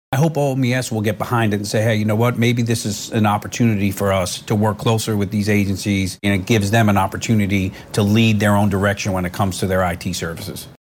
CLICK HERE to listen to commentary from State Senator Paul Rosino.